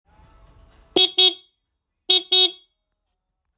Experience the crisp tone and enjoy long-lasting performance!
As a new equivalent to the long discontinued original, this brand new Genuine Nissan Horn for the Nissan Figaro is designed to be a direct replacement to deliver that original, crisp classic tone .
Figaro-Horn-Sound.m4a